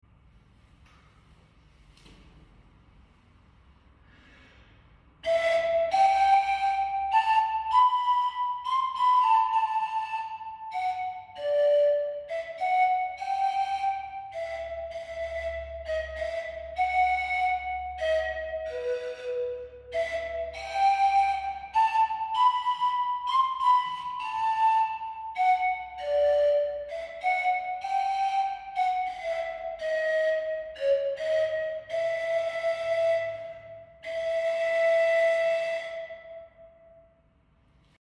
Panpipes Are Number 44 In Sound Effects Free Download